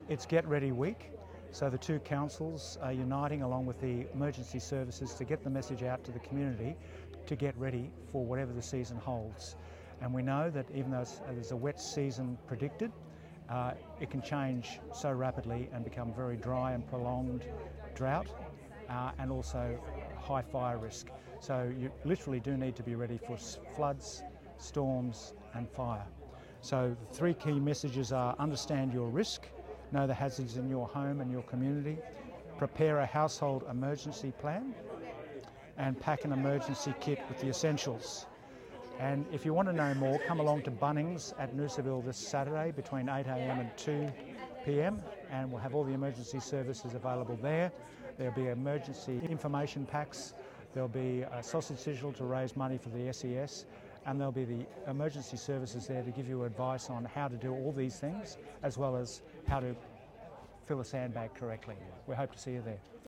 Noosa Mayor Frank Wilkie on how residents can prepare their families, homes and businesses for storm season: